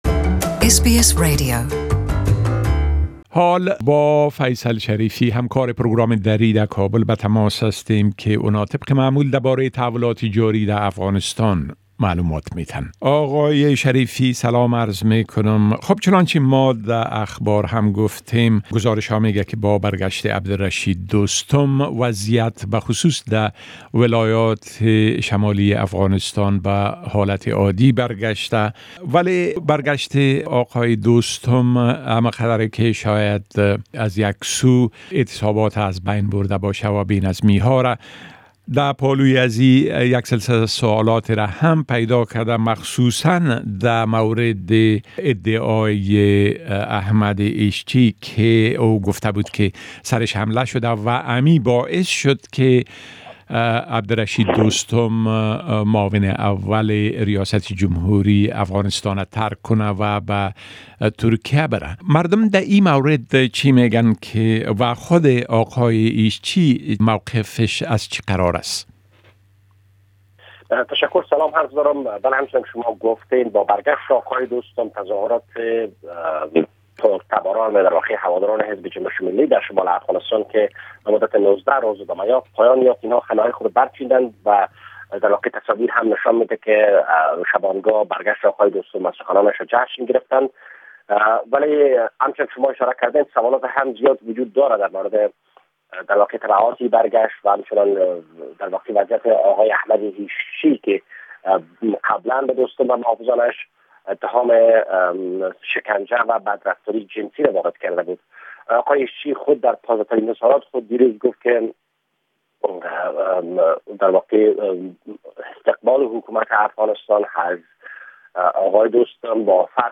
A report from our correspondent in Afghanistan